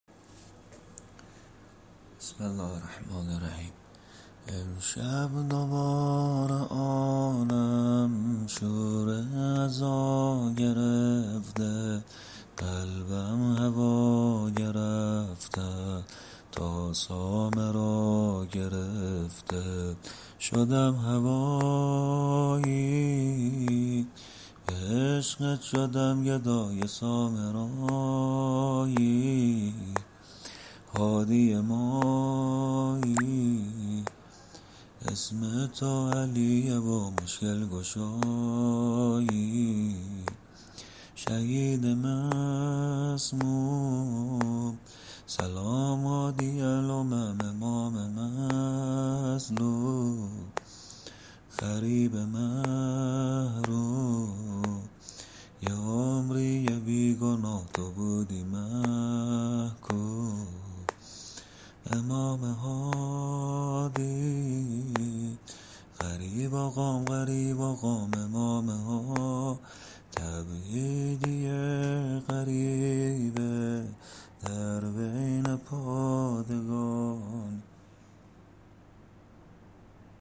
(به سبک ماه حرامه...)